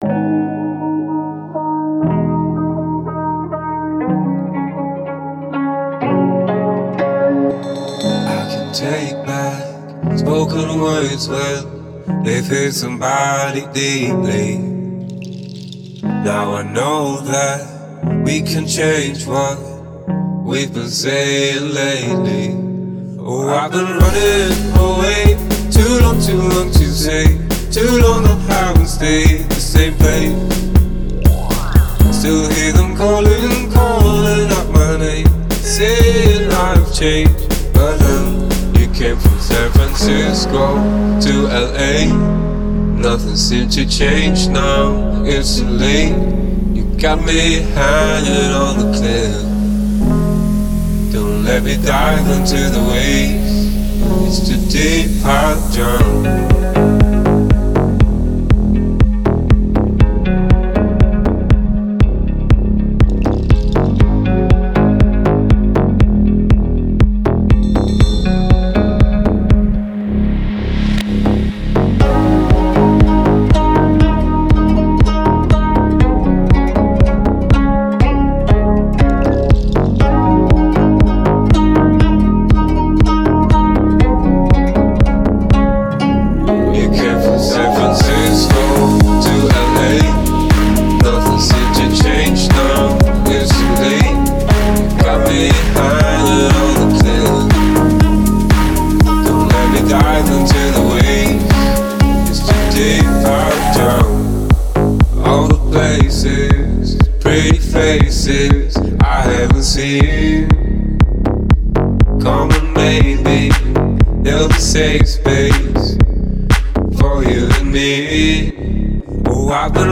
мощная электронная композиция
которая сочетает элементы EDM и даунтемпо.